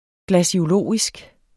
Udtale [ glaɕoˈloˀisg ]